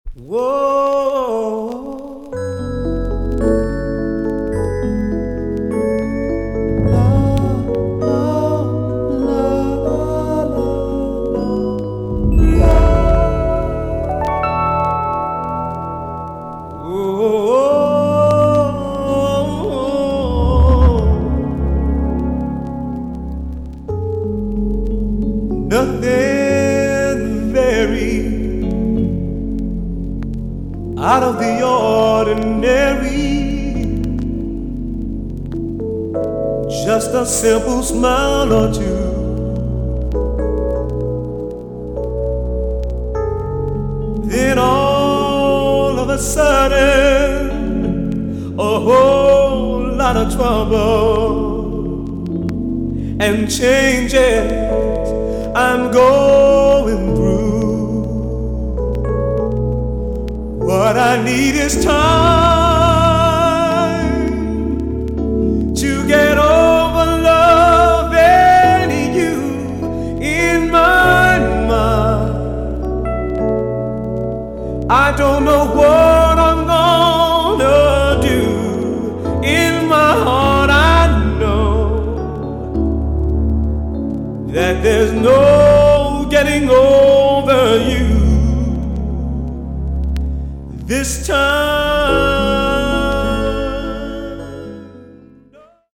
EX-~VG+ 少し軽いチリノイズが入りますが良好です。